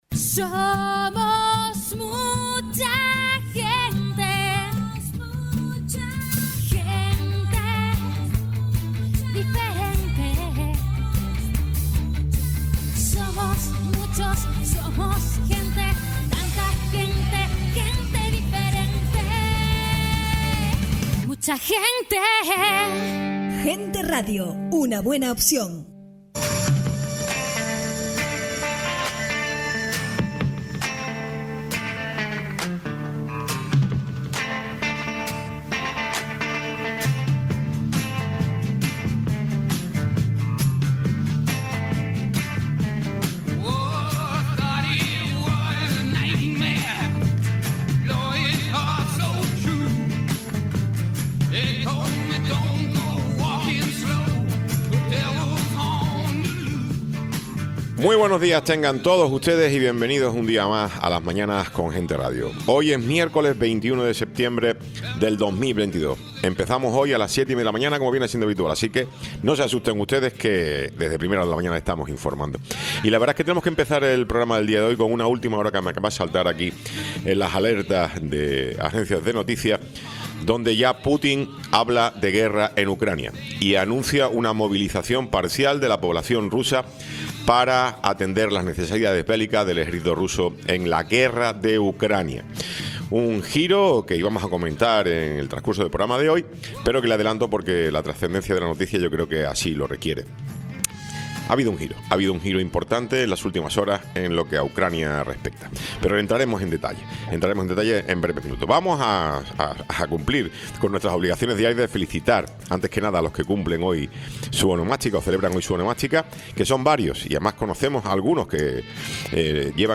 Tiempo de entrevista
Alcalde de Los Realejos
Tertulia